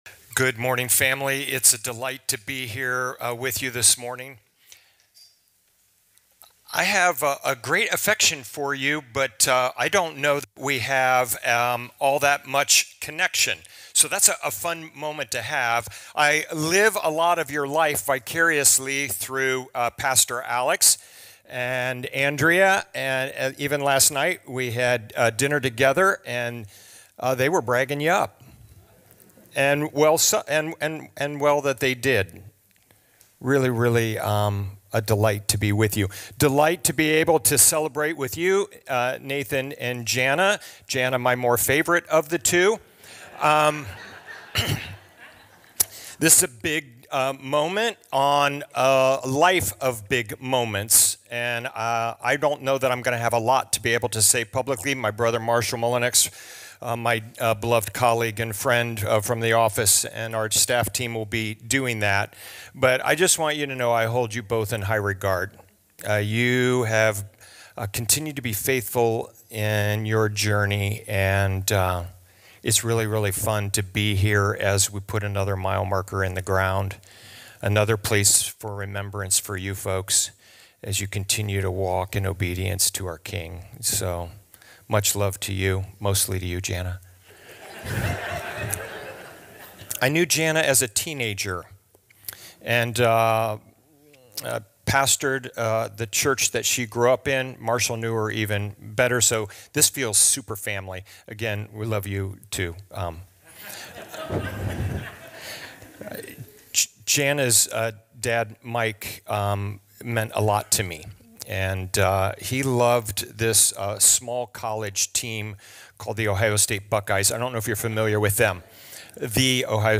Ordination Service